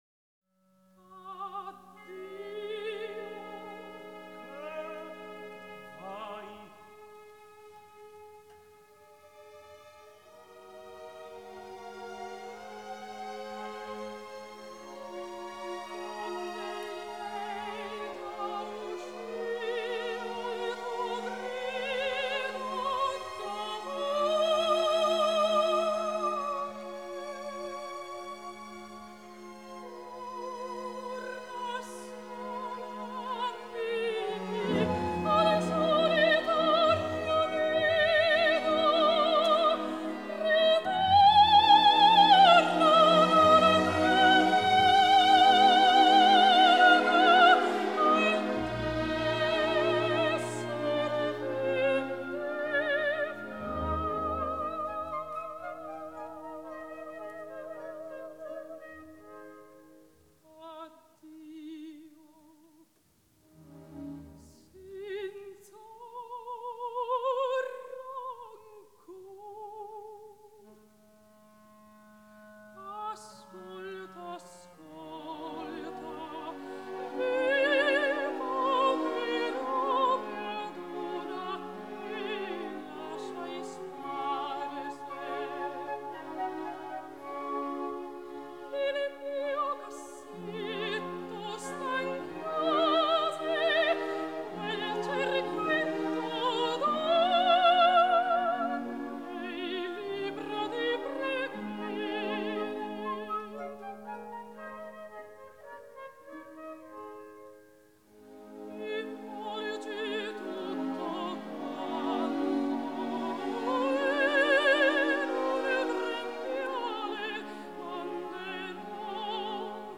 лирическое сопрано